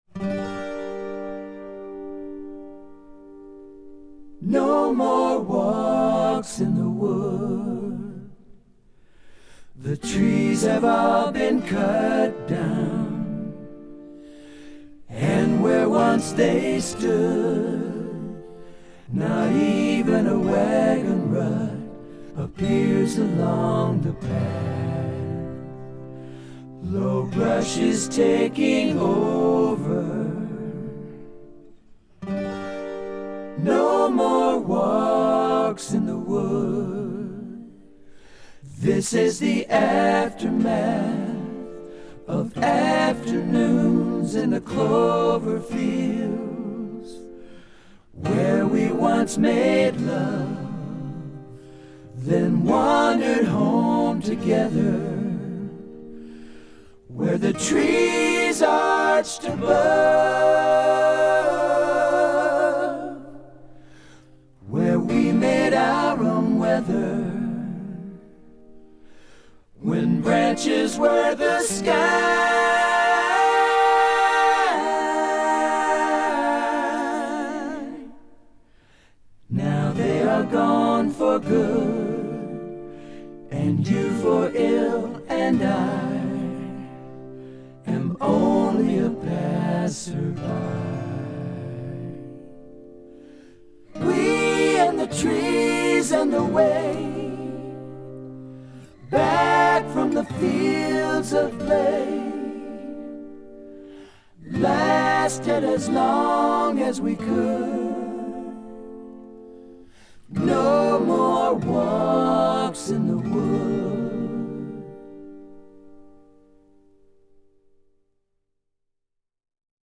> apie vinyl ripus. padarykim eksperimenta
> Головка звукоснимателя: Lyra Skala
> Предварительный усилитель:Whest 40RDT SE
> АЦП: Lynx Hilo